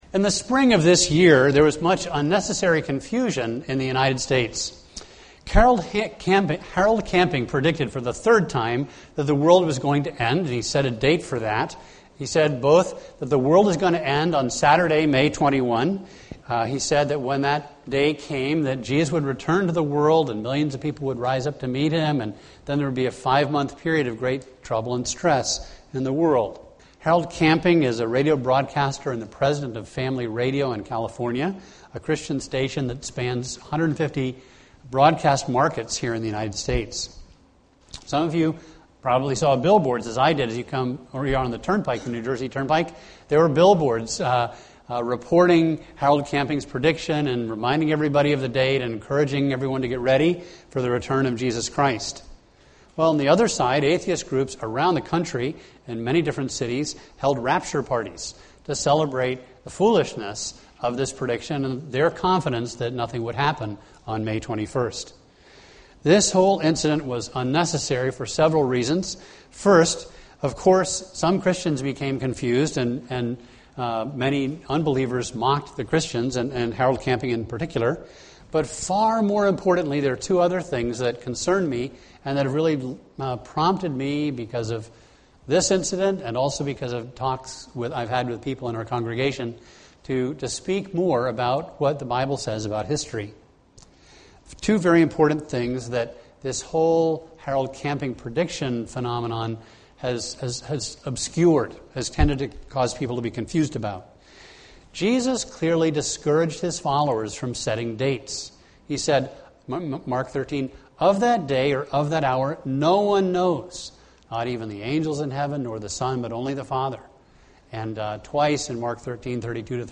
A message from the series "End Times."